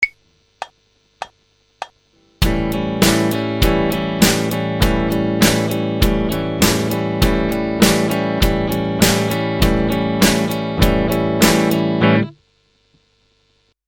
Have a listen to the audio sample to hear how this sounds with a basic chord strumming and compare it to the example played with a straight feel.
straightstrum.mp3